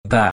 Audio of the phoneme for Commonscript letter 29 (pronounced by male).
Phoneme_(Commonscript)_(Accent_0)_(29)_(Male).mp3